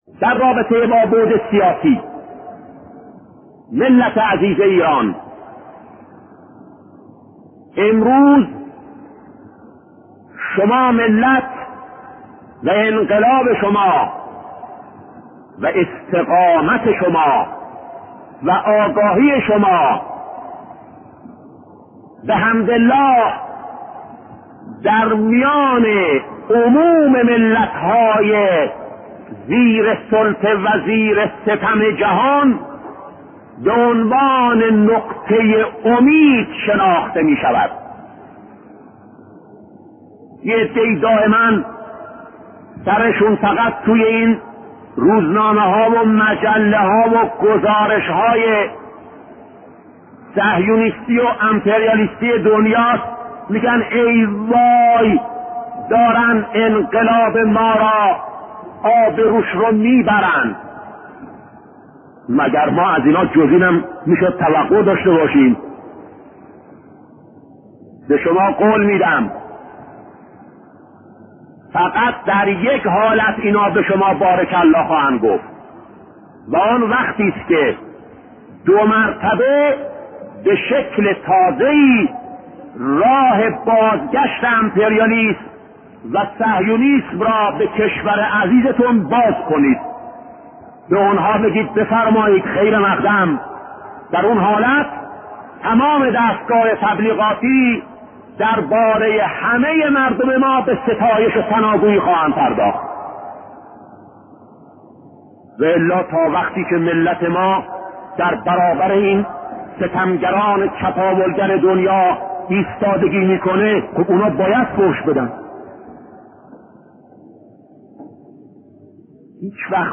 صوت سخنان شهید بهشتی در مورد برکات سیاسی و اقتصادی انقلاب